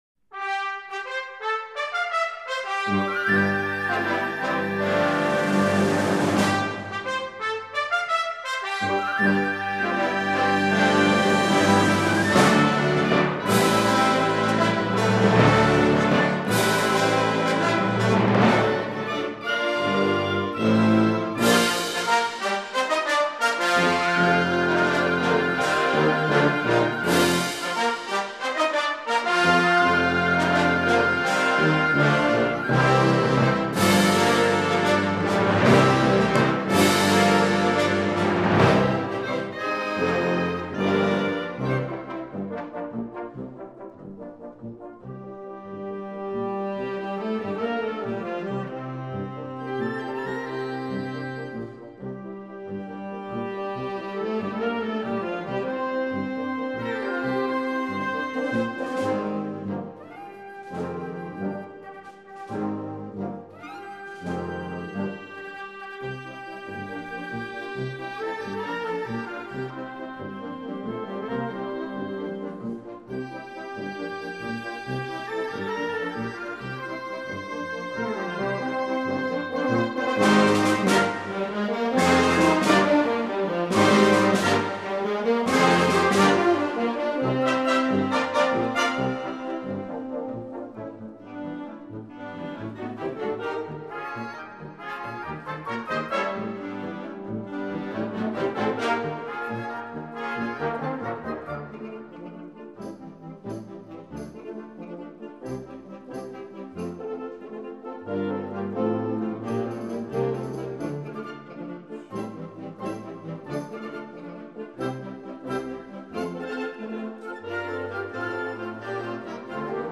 Gattung: Zeitgenössische Originalmusik
Besetzung: Blasorchester